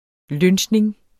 Udtale [ ˈlønɕneŋ ]